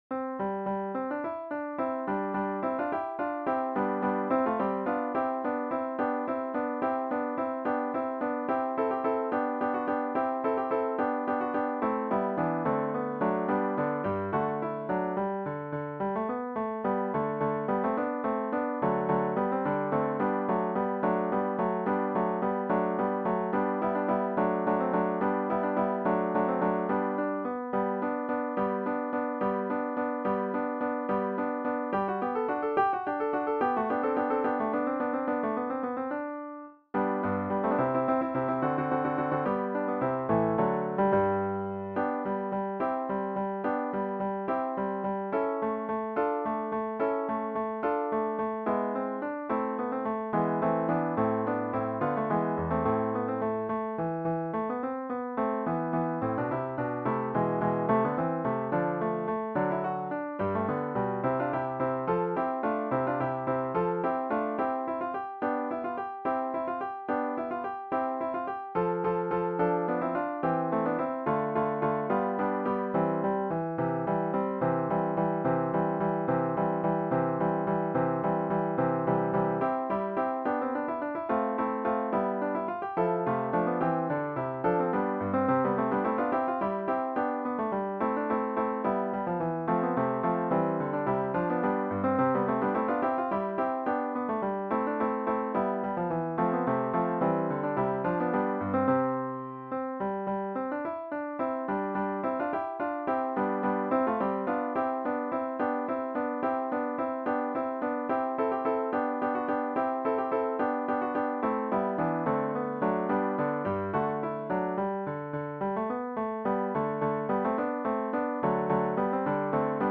for three guitars
This is from the Baroque period.
The tempo is quite fast, but the rhythms are fairly easy.